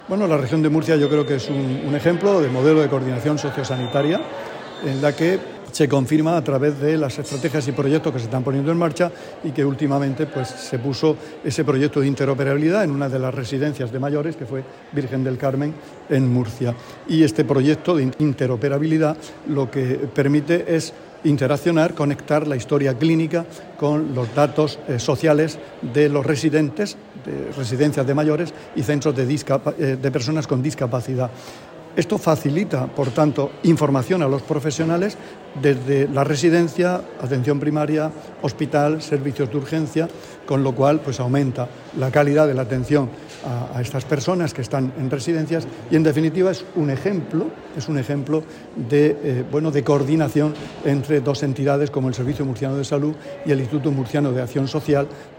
Sonido/ Declaraciones del consejero de Salud, Juan José Pedreño, sobre la implantación del proyecto de interoperabilidad de la Coordinación regional Sociosanitaria.